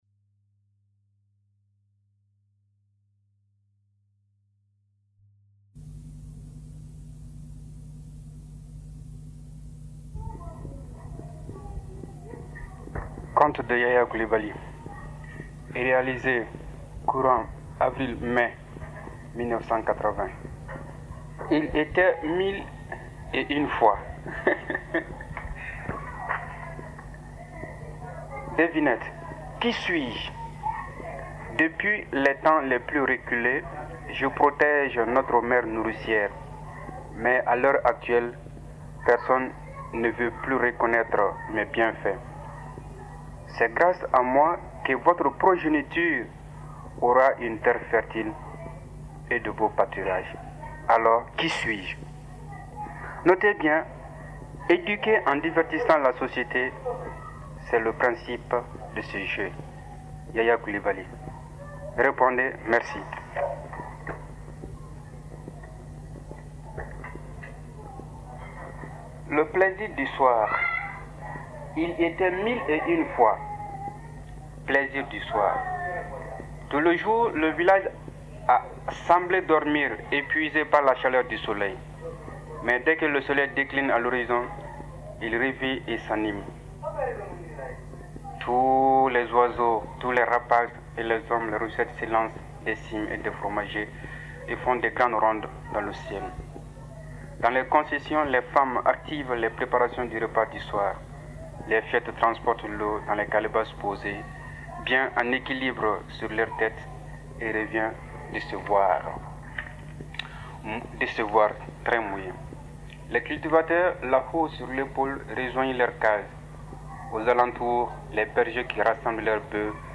Il était mille et une fois : Conte malien